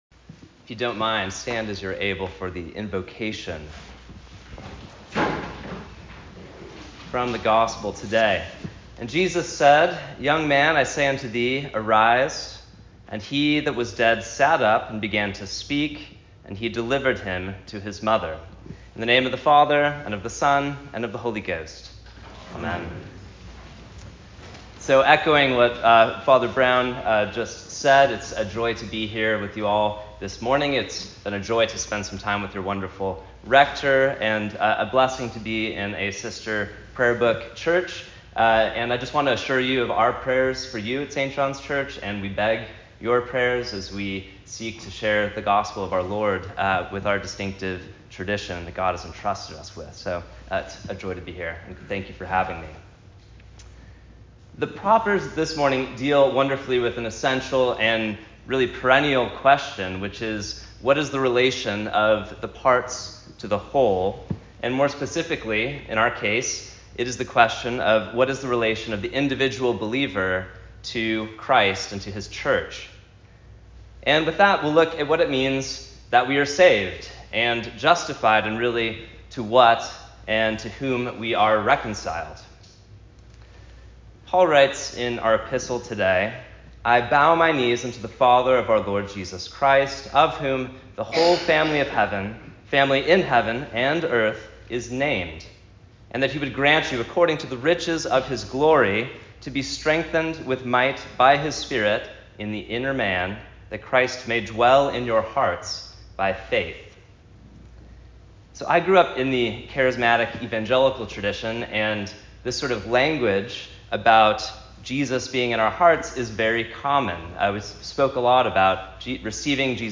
Sermon for the Sixteenth Sunday After Trinity